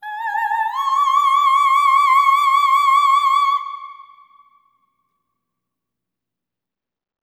OPERATIC16-R.wav